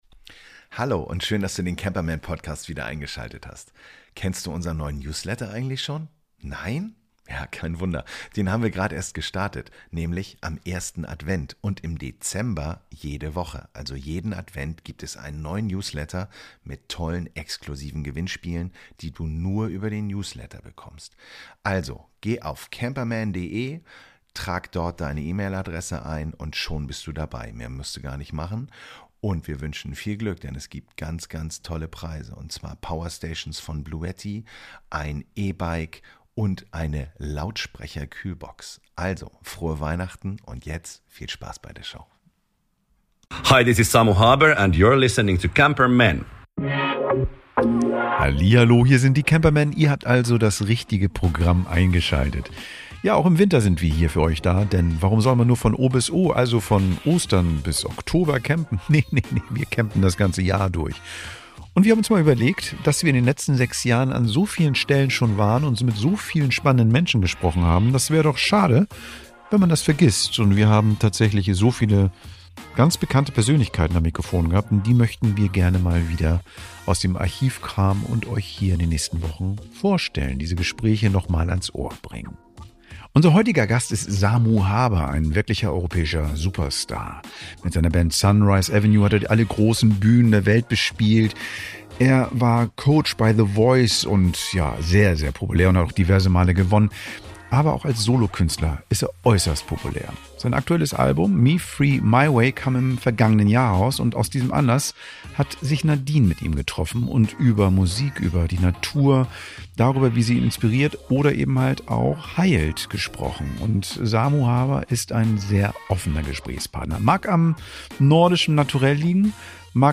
In dieser Folge unserer "VIP-Extras" veröffentlichen wir unser Interview mit dem Sänger Samu Haber.